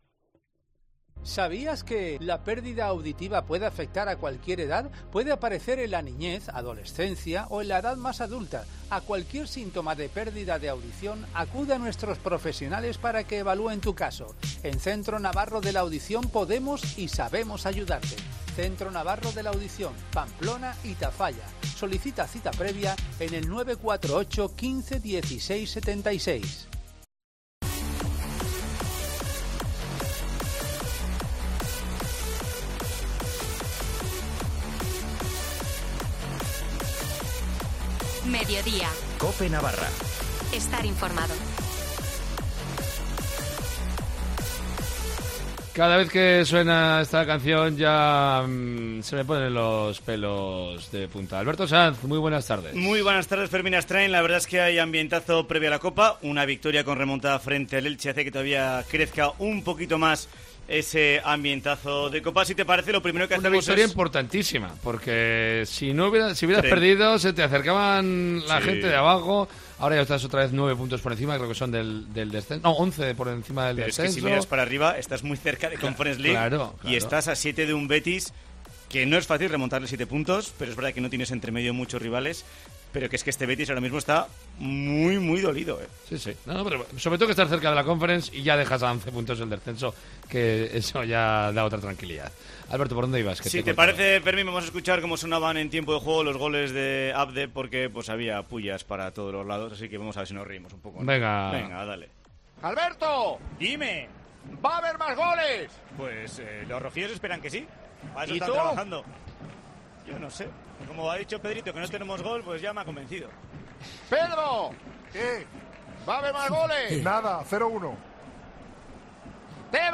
Sonidos de la victoria de Osasuna frente al Elche (2-1)